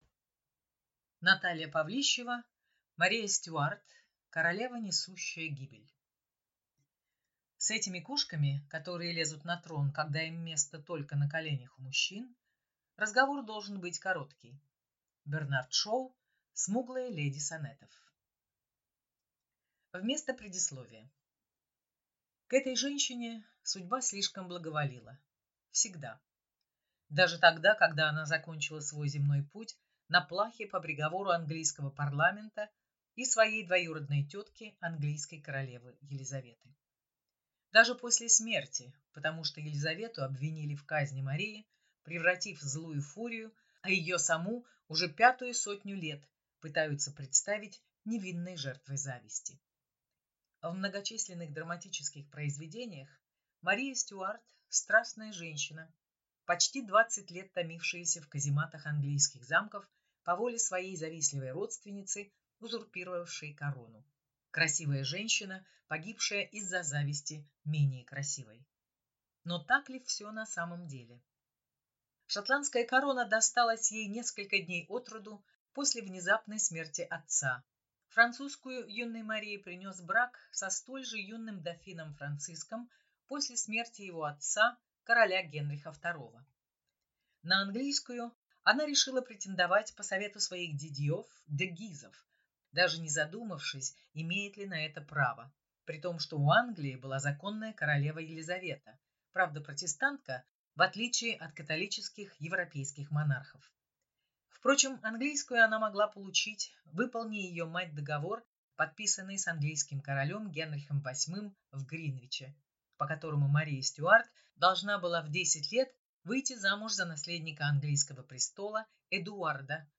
Аудиокнига Мария Стюарт. Королева, несущая гибель | Библиотека аудиокниг